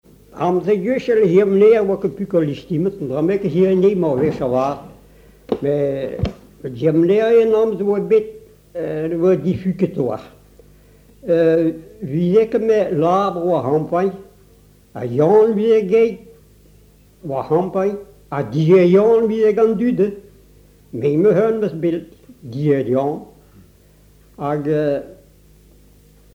Genre conte